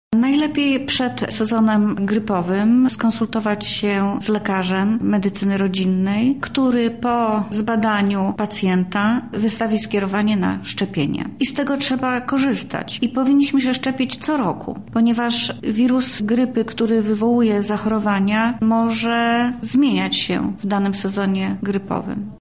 Najlepszą metodą profilaktyki jest szczepionka – mówi Anna Strzyż, Zastępca Lubelskiego Państwowego Wojewódzkiego Inspektora Sanitarnego w Lublinie: